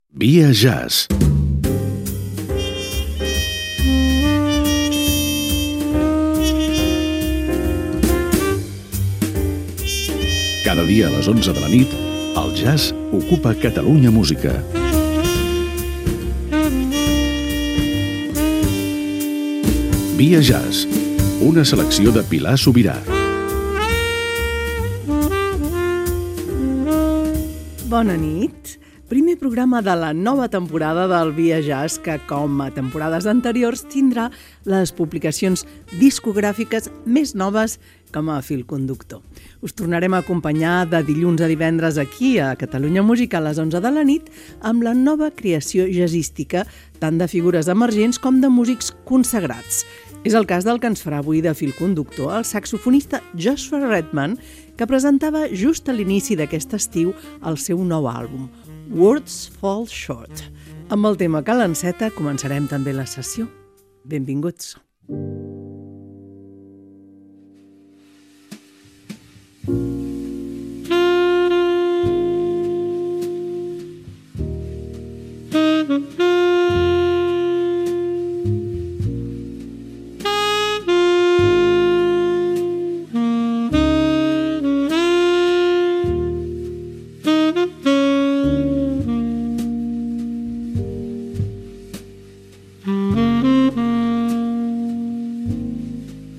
Careta del programa, presentació del primer programa de la temporada 2025-2026 i tema musical
Musical